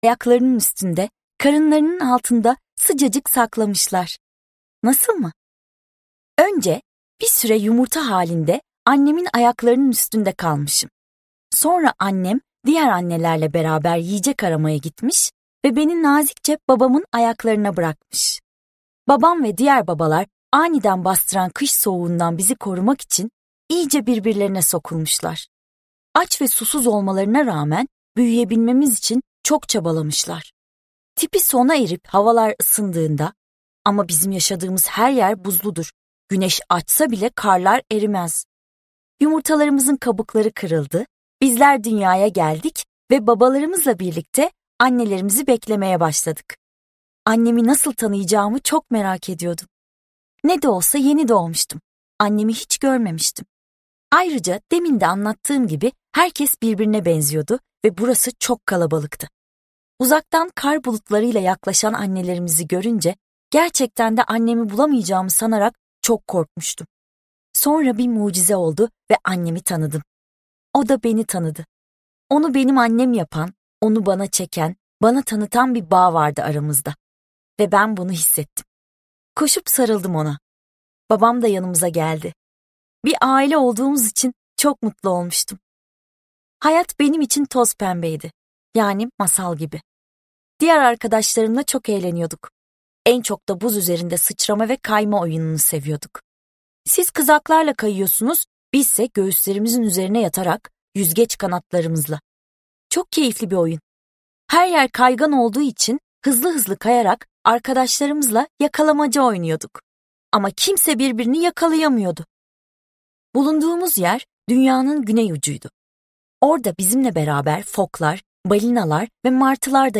Renkli Penguencik - Seslenen Kitap